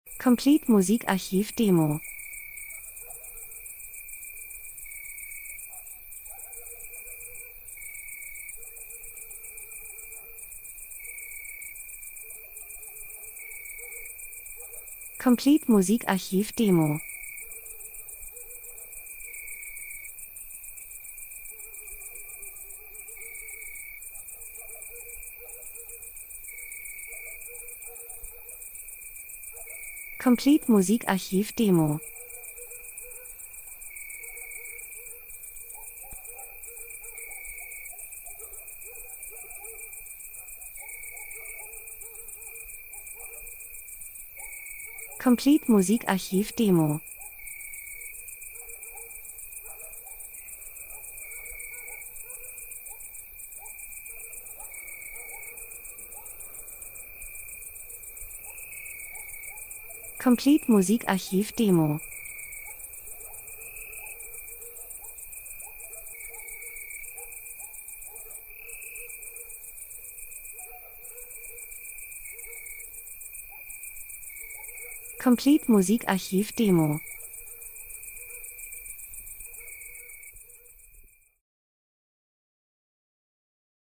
Sommer -Geräusche Soundeffekt Natur Grillen Abendstimmung 01:23